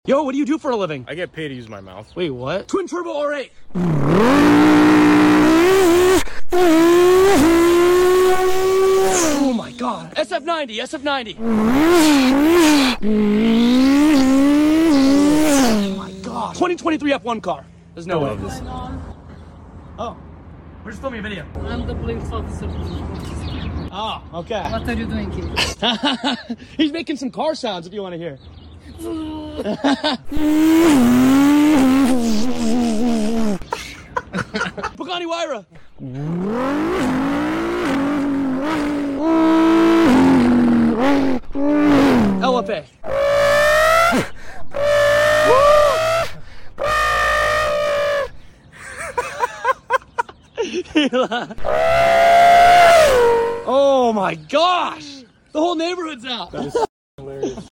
He Can Make Any Car Sound Imaginable